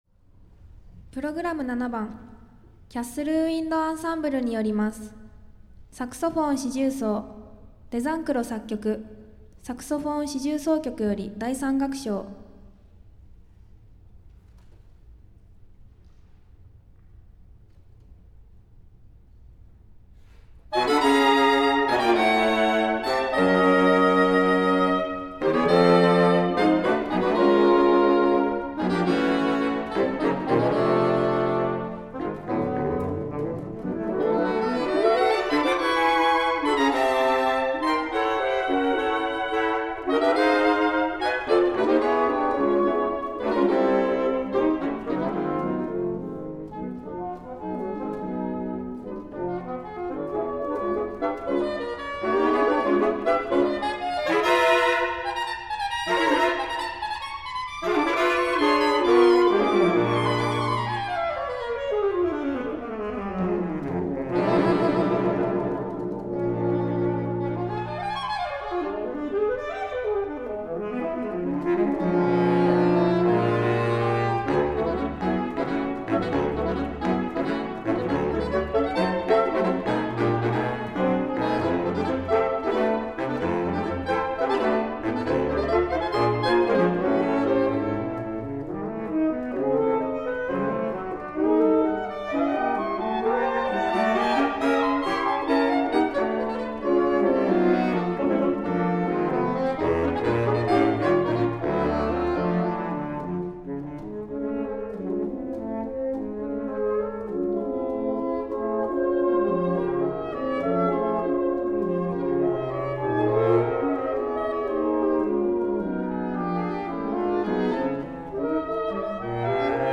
第52回大阪府大会アンサンブルコンテスト、無事に終了いたしました！
場　所：大東市立総合文化会館サーティホール（大ホール）
編　成：サクソフォーン四重奏